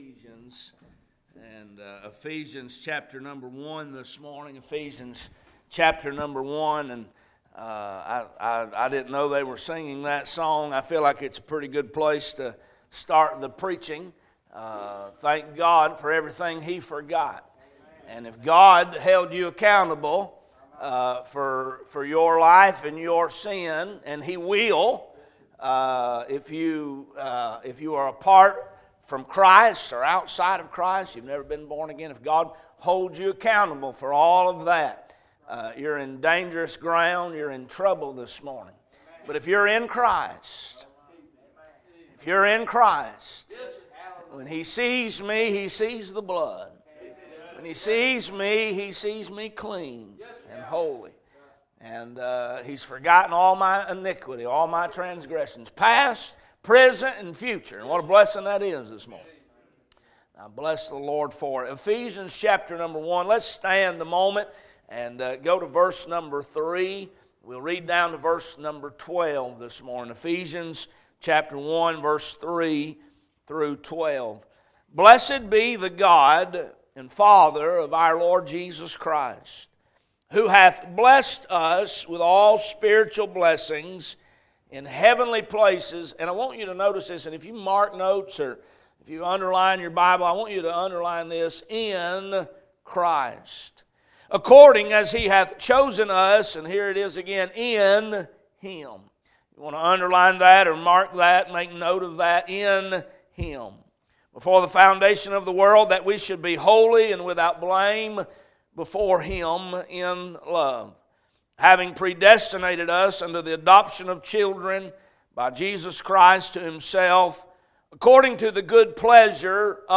Sermons - Crossroads Baptist Church